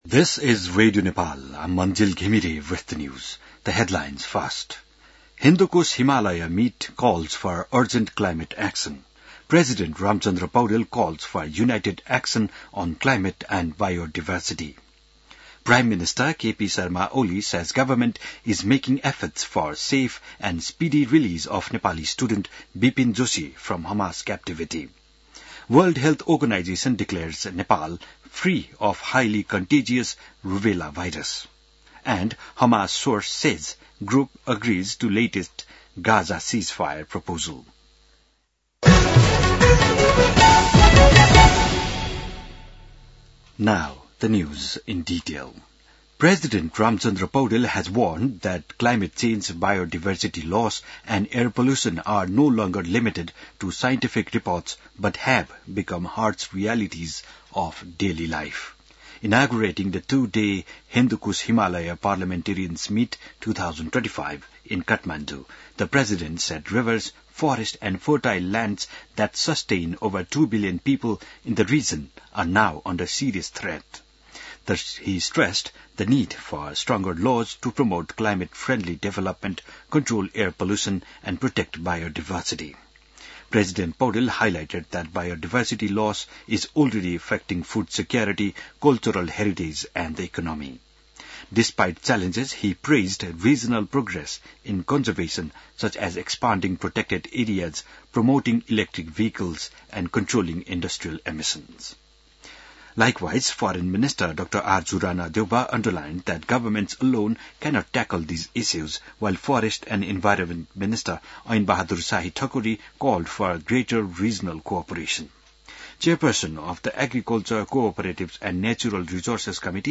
बिहान ८ बजेको अङ्ग्रेजी समाचार : ३ भदौ , २०८२